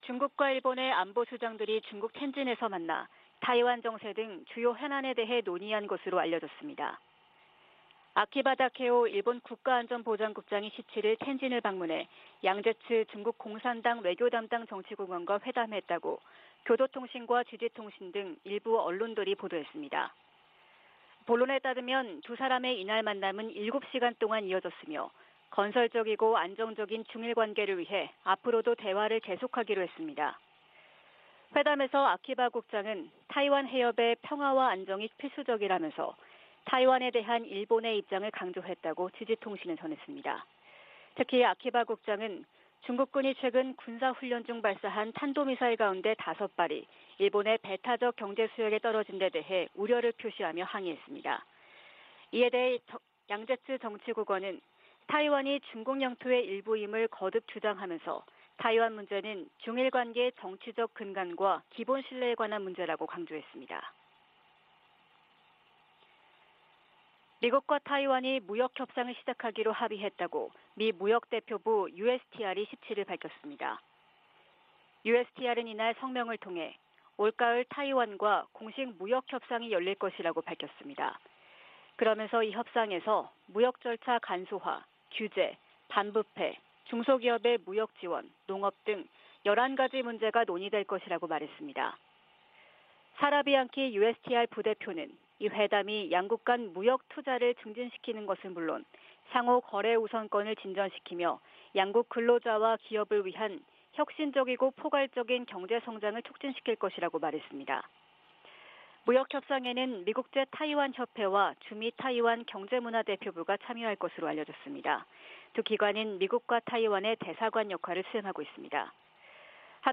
VOA 한국어 '출발 뉴스 쇼', 2022년 8월 19일 방송입니다. 한국 정부가 ‘담대한 구상’과 관련한 구체적인 대북 메시지를 발신하고 북한이 수용할 수 있는 여건을 조성해나갈 것이라고 밝혔습니다. 이종섭 한국 국방부 장관이 서울 국방부 청사에서 폴 나카소네 미국 사이버사령관을 접견하고 북한 등 사이버 위협에 대해 협력 대응하기로 했습니다. 미 국무부는 북한의 도발적 행동에 변화가 없다면 제재는 계속될 것이라고 밝혔습니다.